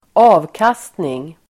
Uttal: [²'a:vkas:tning]